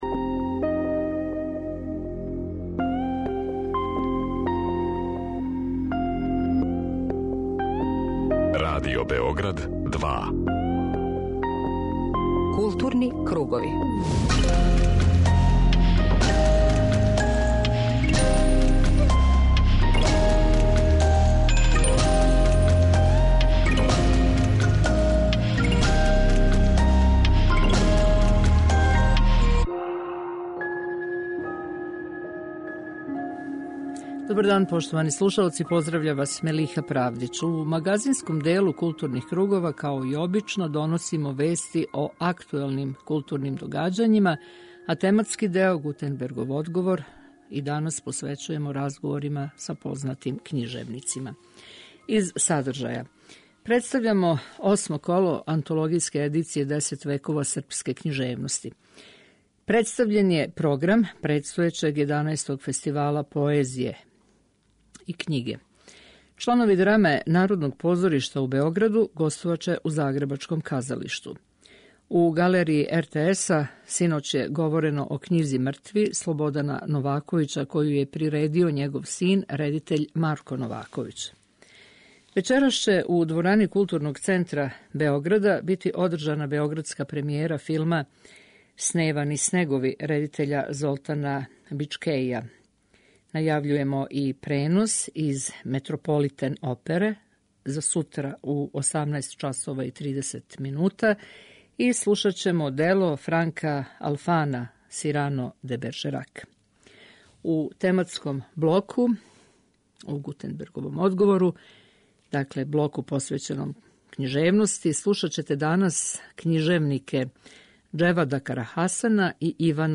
У магазинском делу 'Културних кругова' доносимо вести о актуелним културним догађајима, a тематски део, 'Гутенбергов одговор', данас посвећујемо разговорима са књижевним ствараоцима из Сарајева Џевадом Карахасаном и Иваном Ловреновићем.